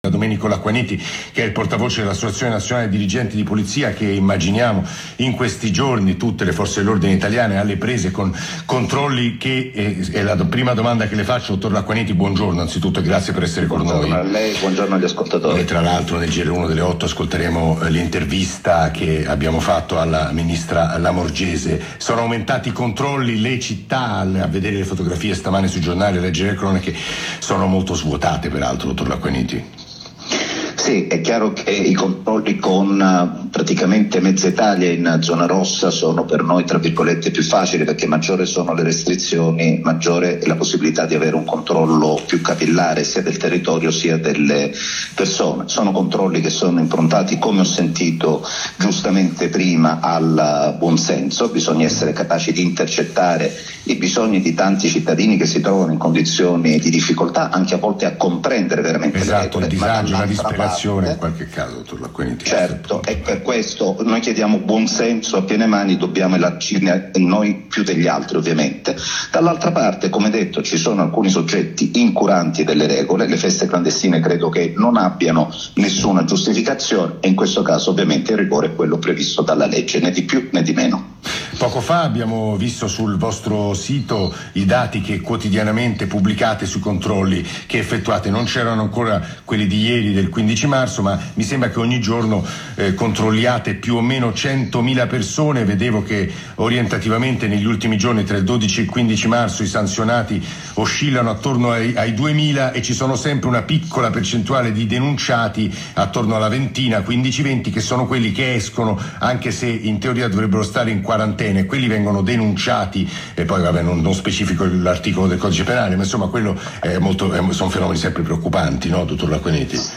Intervento a Radio Uno Radio Anch'io